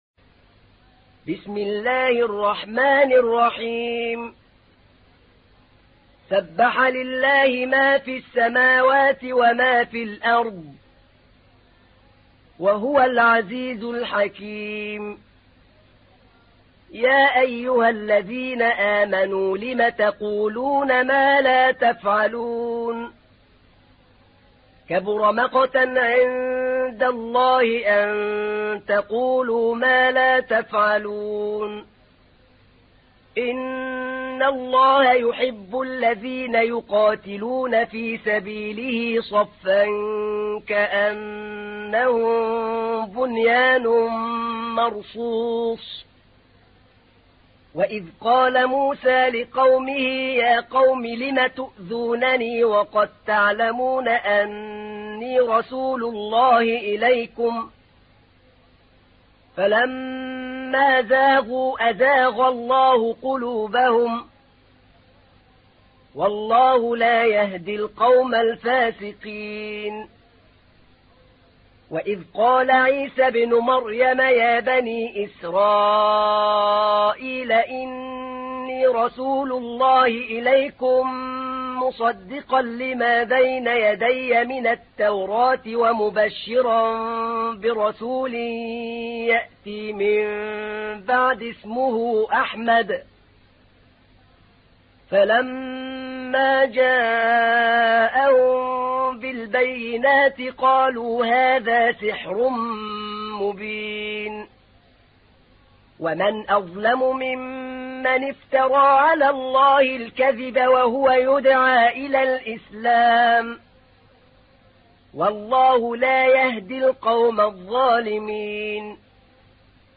تحميل : 61. سورة الصف / القارئ أحمد نعينع / القرآن الكريم / موقع يا حسين